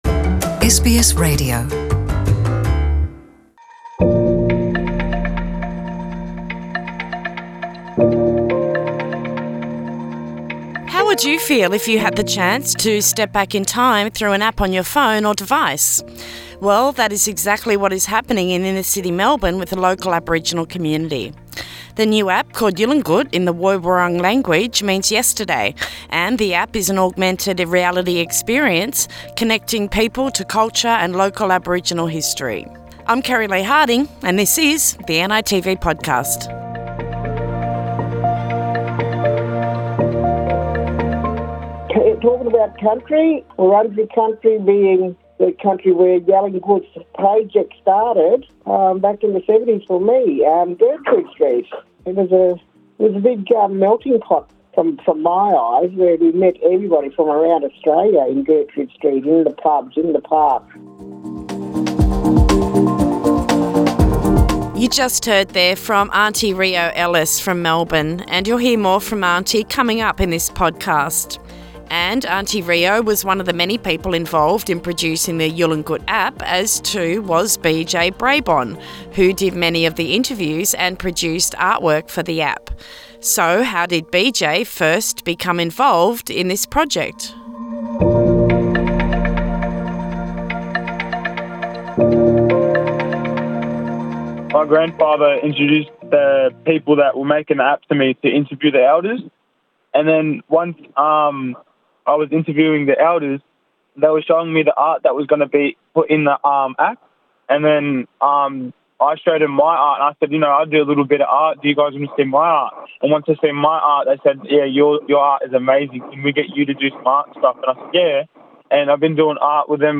Interviews about the Yalinguth App